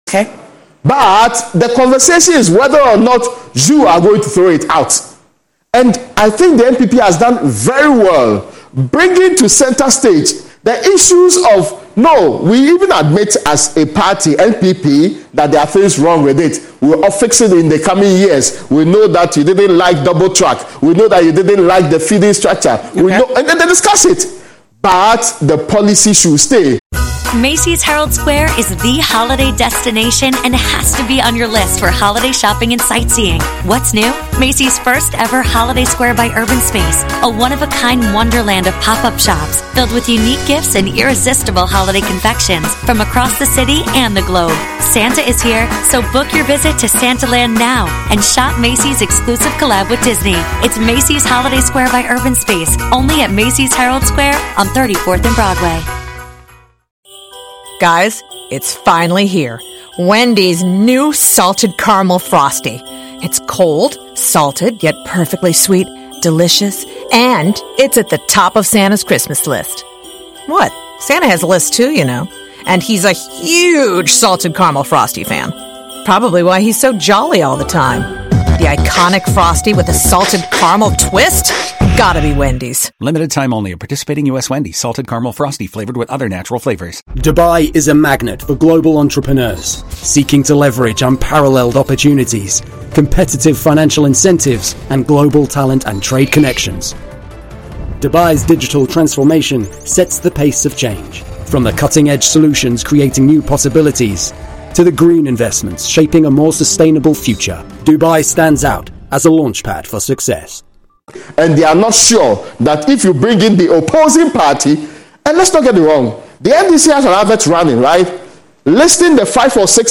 An afternoon news and current affairs programme showcasing news updates of the day, local and international news analysis. It also includes breaking and developing stories, sports and entertainment.